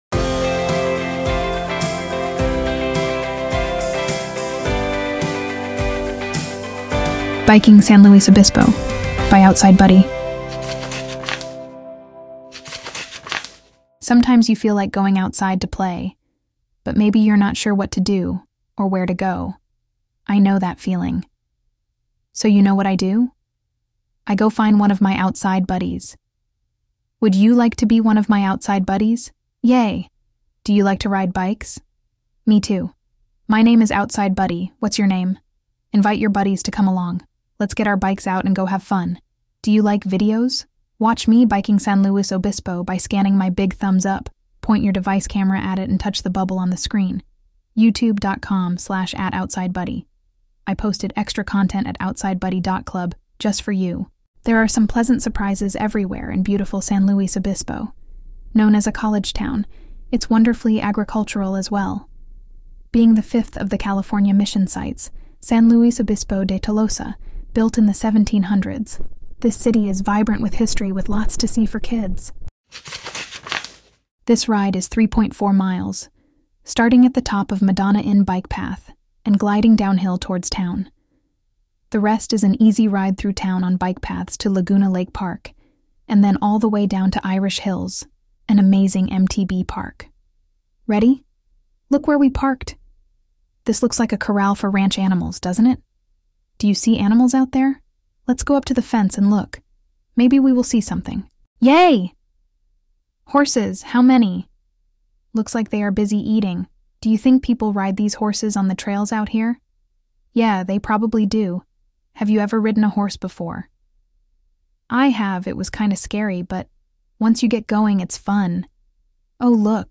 Free AudioBook!